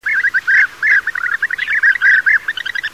Żołna pszczołojad - Merops apiaster
głosy